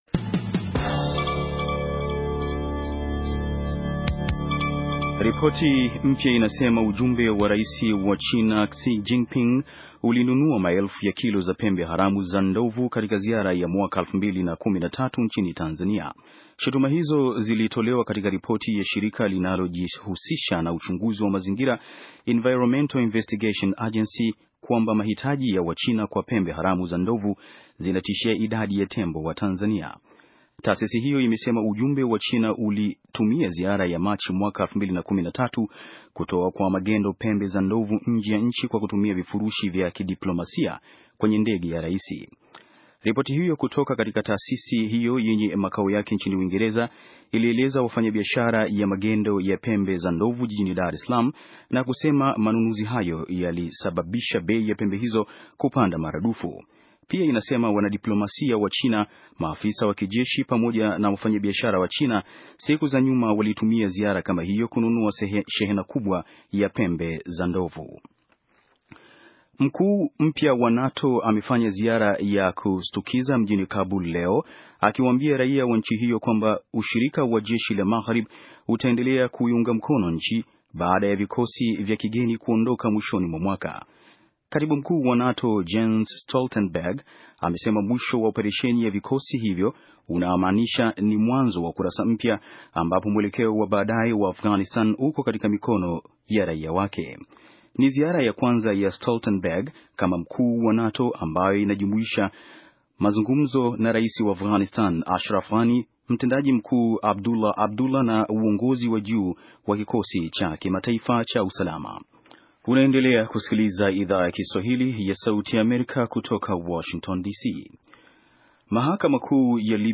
Taarifa ya habari - 6:41